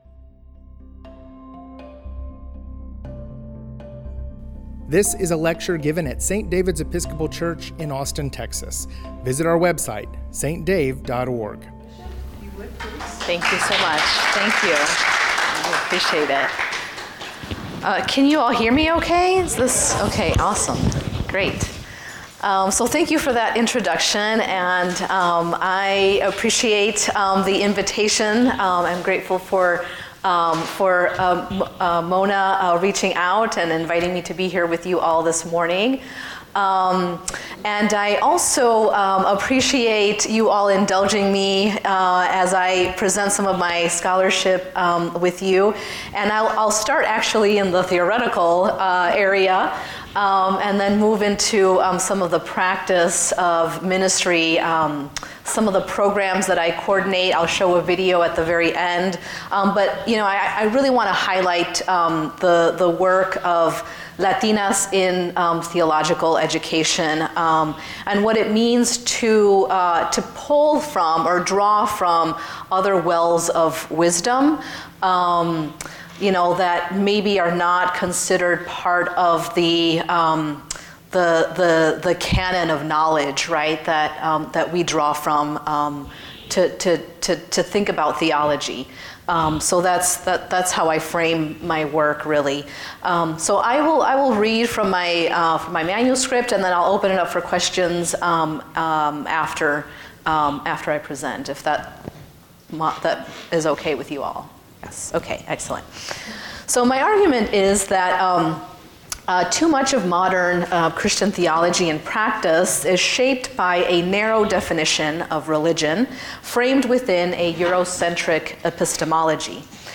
This lecture will highlight the ministry and scholarship of Latinas and how they are imaging and implementing new possibilities for faith formation and theological education. The presentation will engage decolonizing pedagogies, including intergenerational, peer-learning, and accompaniment mentorship practices.